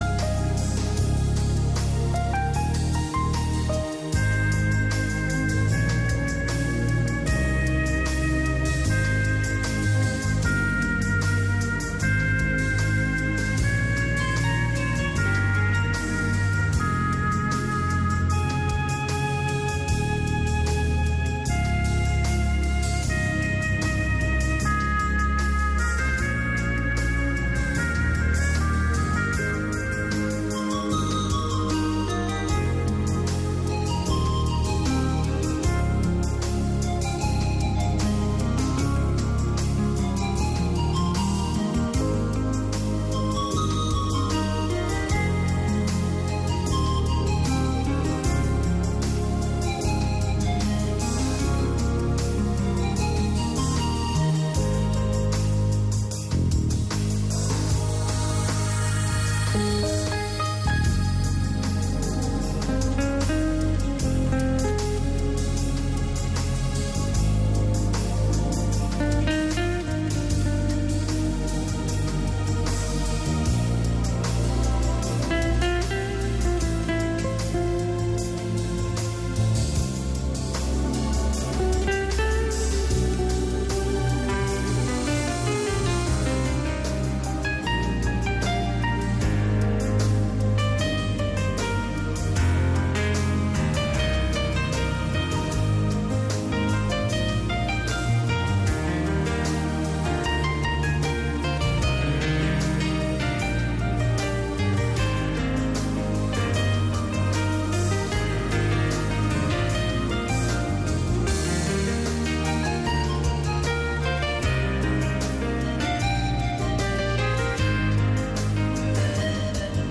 Cada dia pots seguir la Missa en directe amb Ràdio Estel.